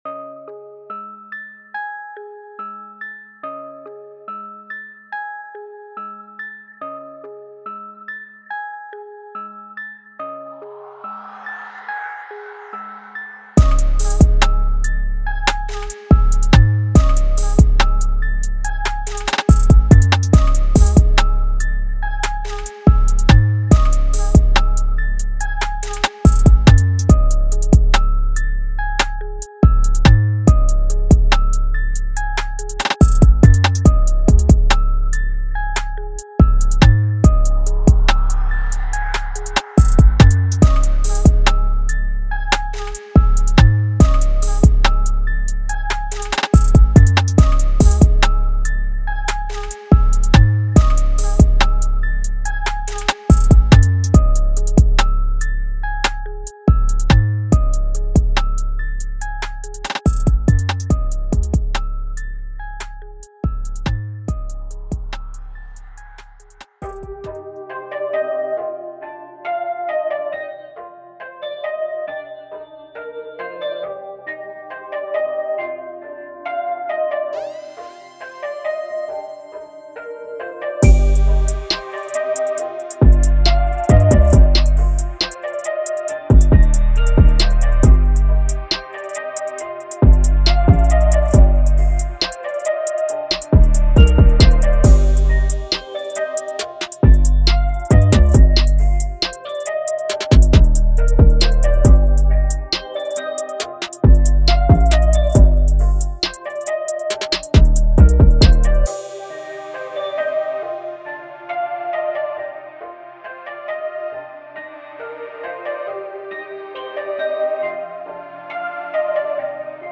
سمپل پک دریل